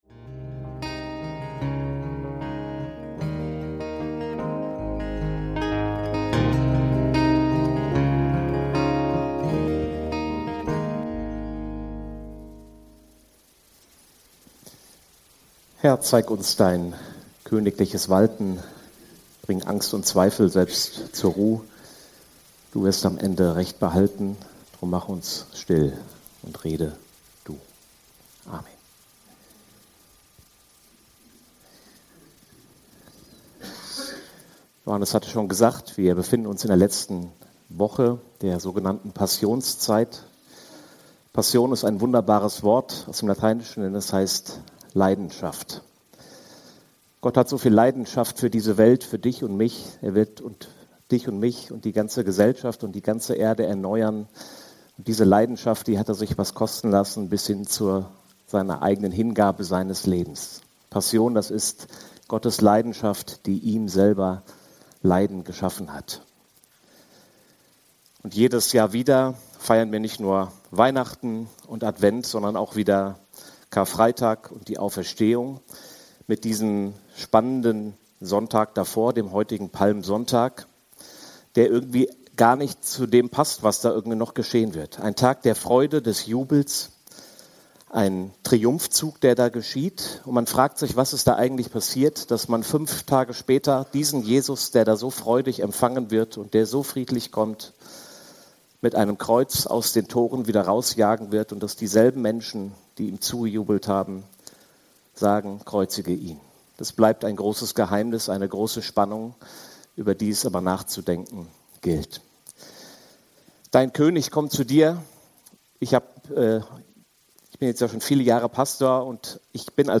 Dein König kommt zu dir – Predigt vom 29.03.2026